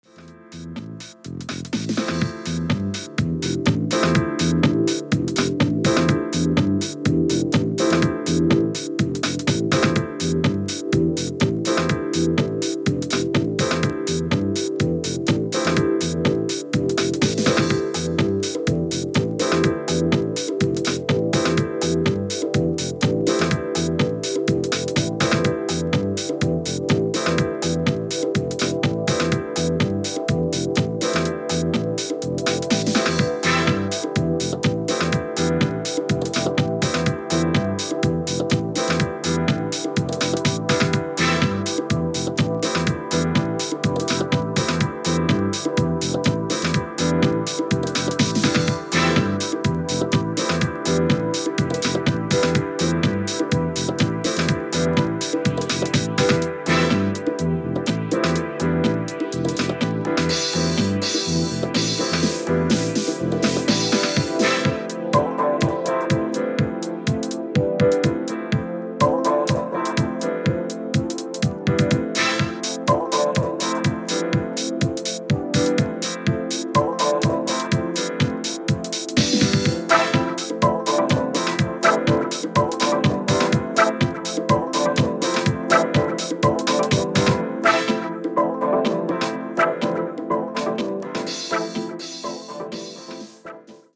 時間軸関係ない長く使えるDeep House 4曲収録12"です。